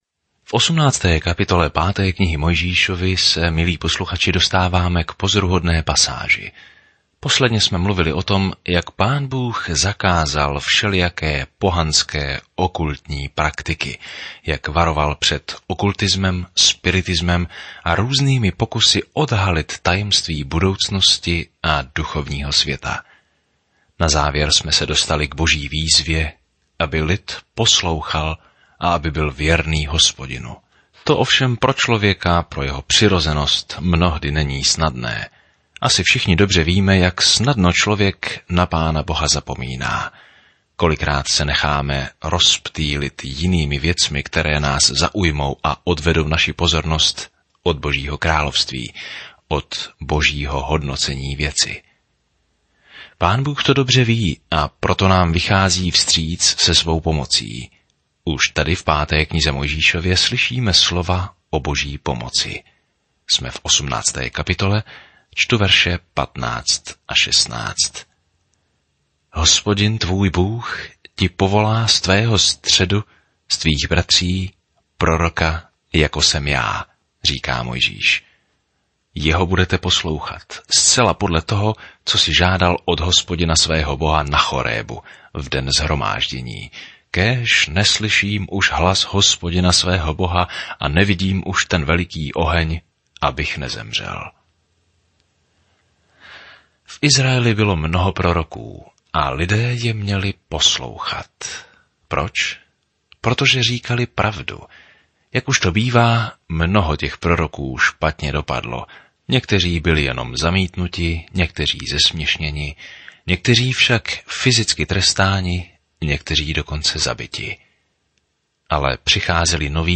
Písmo Deuteronomium 18:15-22 Deuteronomium 19 Deuteronomium 20:1 Den 17 Začít tento plán Den 19 O tomto plánu Deuteronomium shrnuje dobrý Boží zákon a učí, že poslušnost je naší odpovědí na jeho lásku. Denně procházejte Deuteronomium a poslouchejte audiostudii a čtěte vybrané verše z Božího slova.